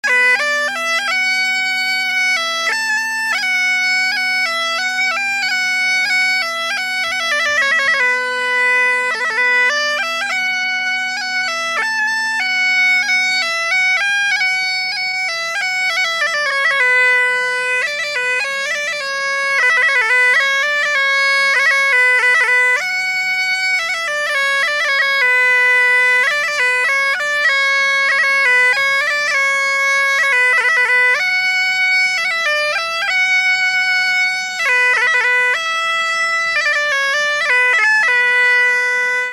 Danion de la Garnache (Le) Détails de l'archive Titre Danion de la Garnache (Le) Origine du titre : interprète Note Enregistrement effectué lors de la fête de la veuze, le 22 octobre 1989 à Fonteclose (La Garnache).
Résumé Instrumental
Pièce musicale inédite